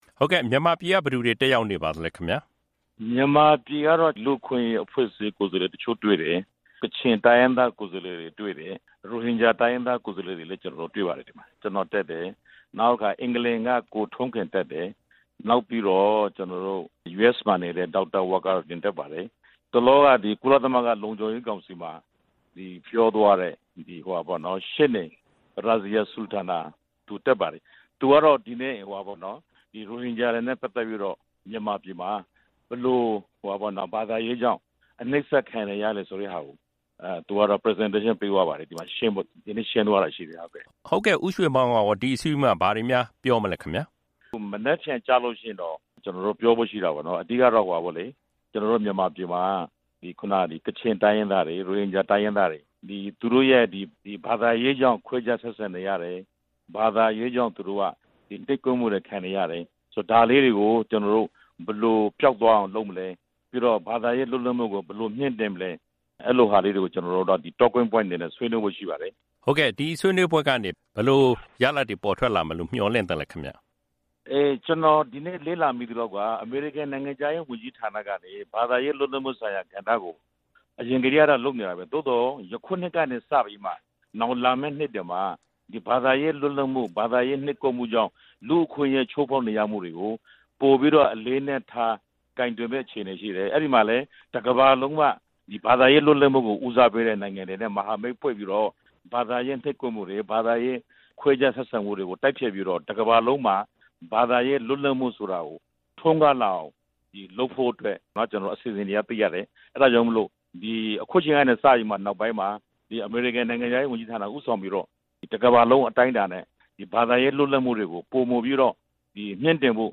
ဆက်သွယ်မေးမြန်းထားပါတယ်